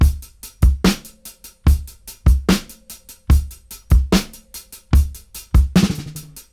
73-DRY-08.wav